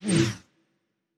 pgs/Assets/Audio/Sci-Fi Sounds/Electric/Device 1 Stop.wav at master
Device 1 Stop.wav